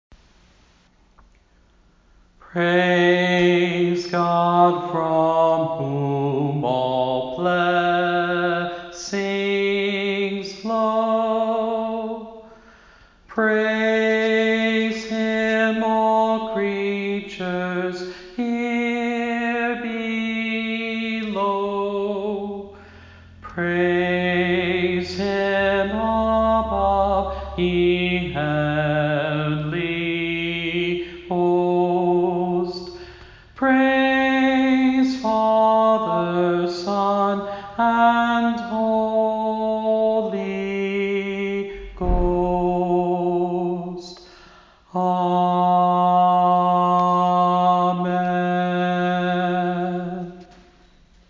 The rectory’s empty living room offer amazing acoustics in which to glorify God!
rectory-doxology.mp3